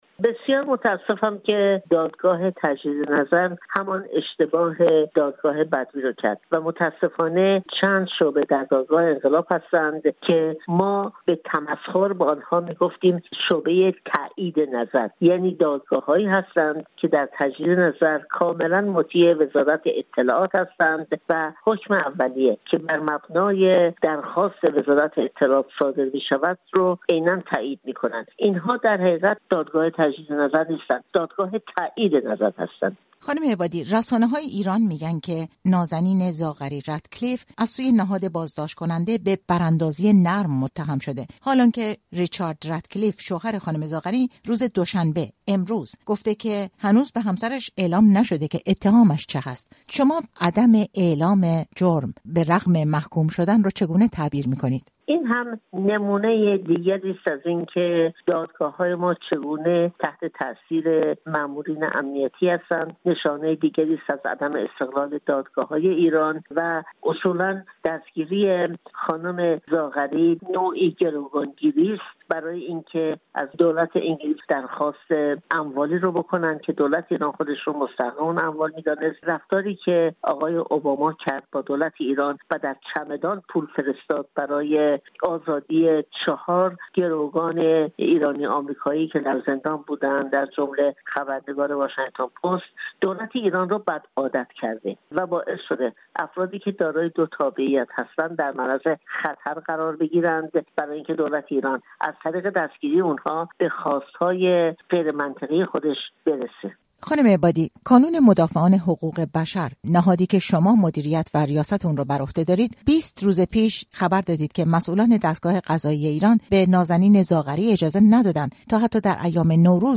شیرین عبادی، برنده حایزه صلح نوبل، با رادیو فردا در مورد تایید حکم زندان نازنین زاغری،شهروند ایرانی-بریتانیایی به گفتگو نشسته است.